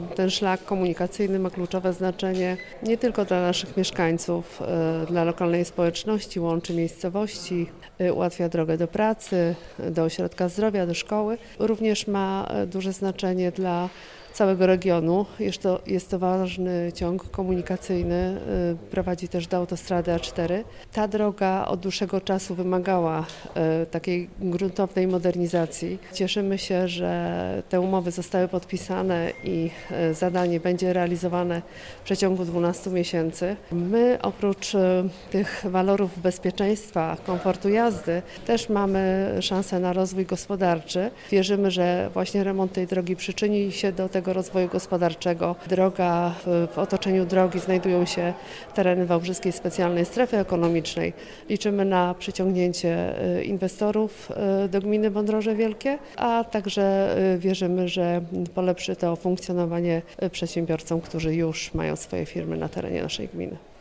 – To wydarzenie długo wyczekiwane przez mieszkańców, przez lokalną społeczność – tak o przebudowie drogi nr 345 mówi Elżbieta Jedlecka, wójt gminy Wądroże Wielkie.